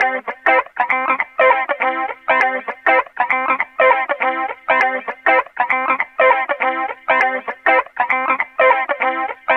Loops guitares rythmique- 100bpm 3
Guitare rythmique 55